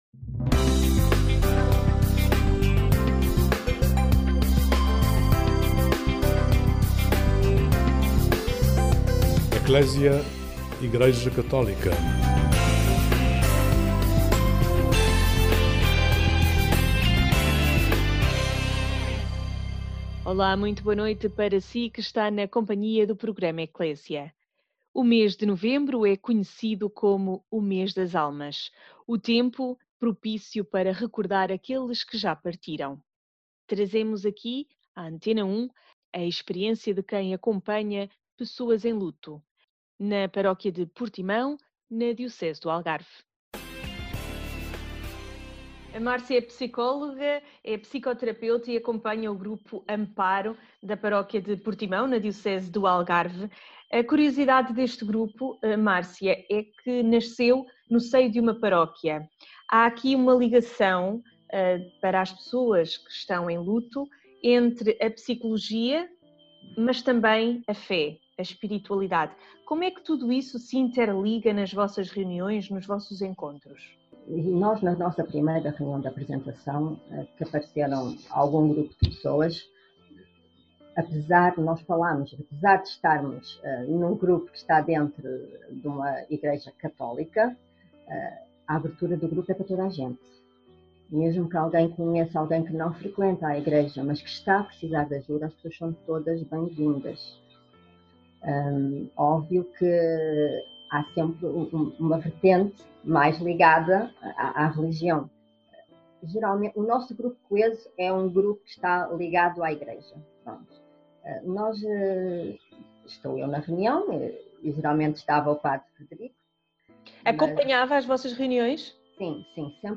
conversou com o programa de rádio Ecclesia